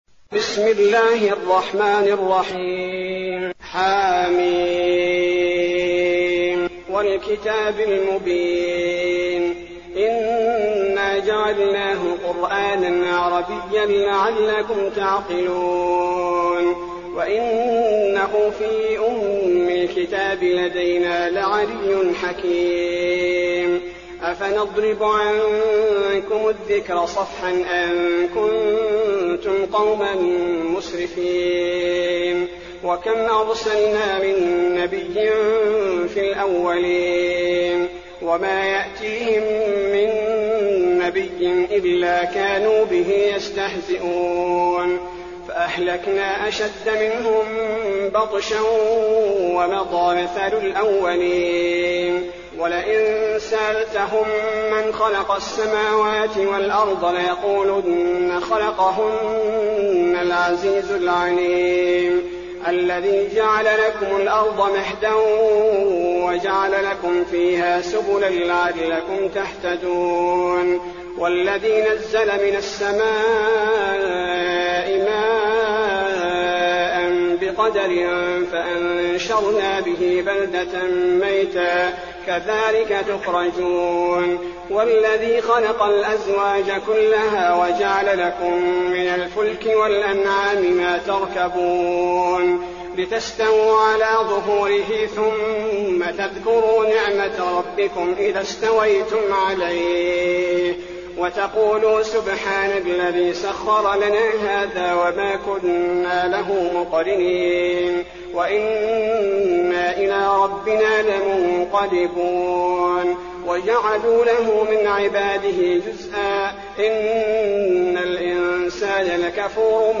المكان: المسجد النبوي الزخرف